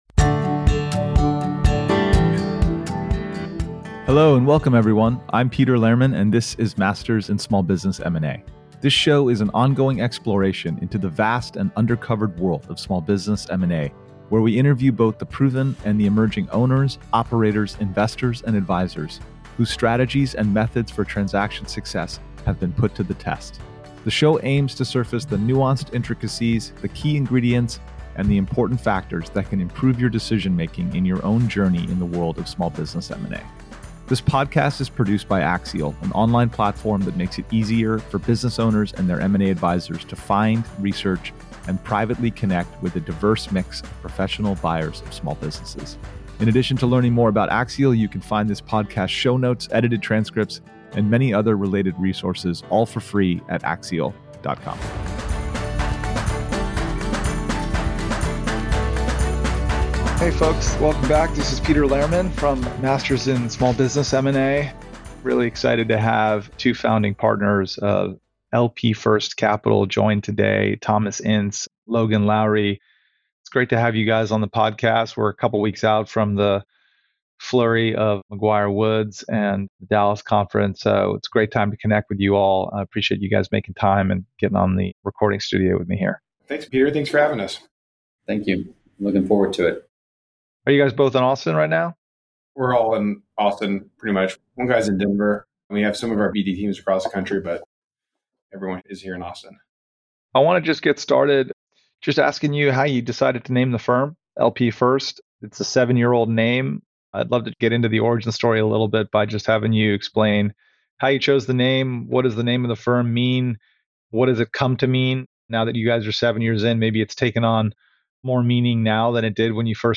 The conversation closes with reflections on hold vs. exit decisions, the firm's evolution, and what lies ahead.